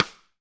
SD RI36.wav